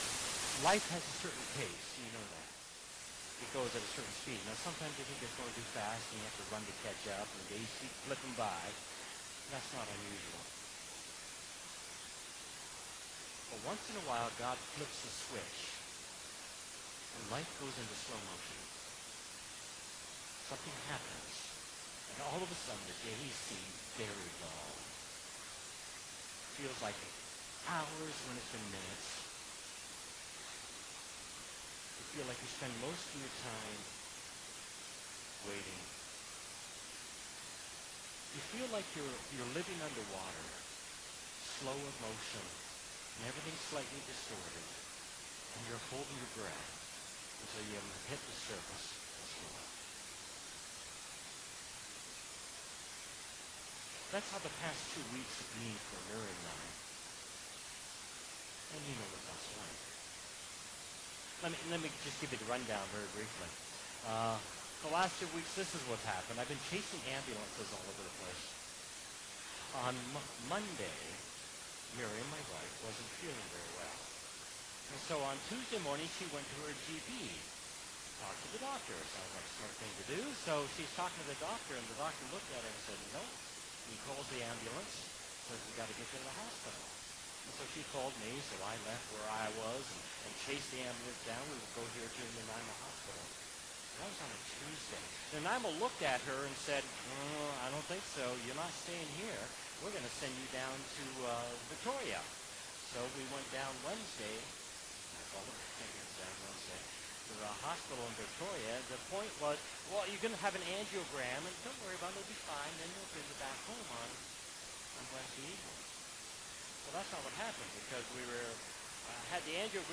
*WE APOLOGIZE FOR THE POOR AUDIO IN TODAY'S RECORDING DUE TO TECHNICAL ISSUES*